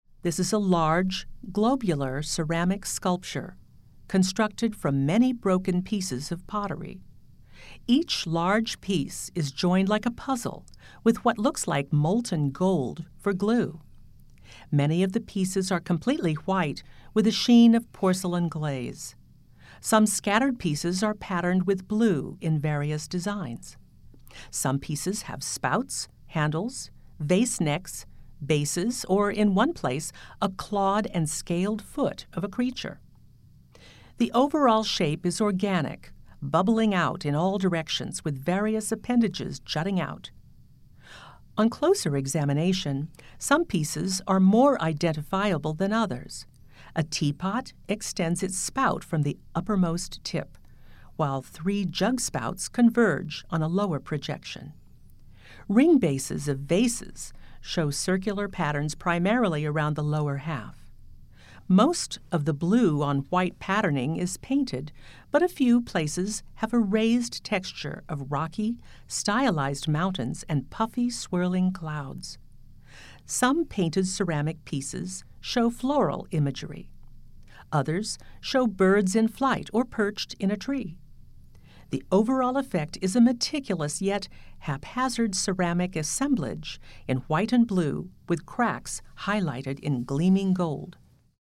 Audio Description